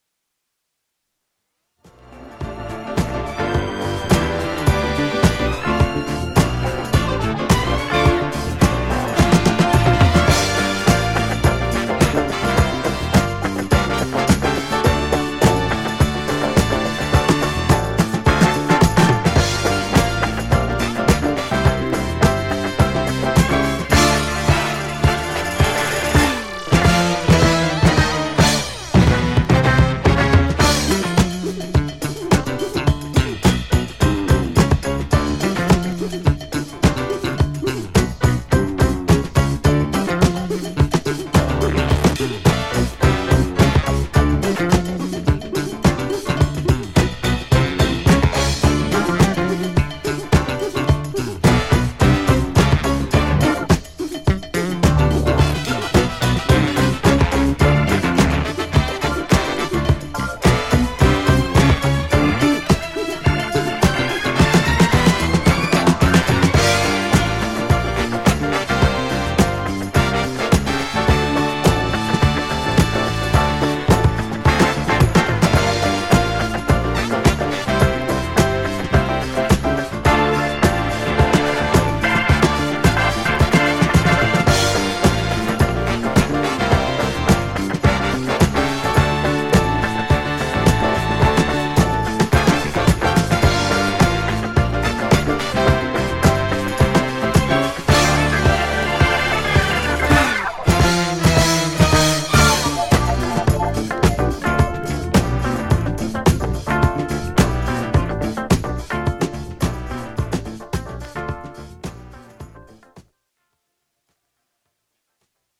INSTRUMENTAL
ジャンル(スタイル) DISCO / SOUL / FUNK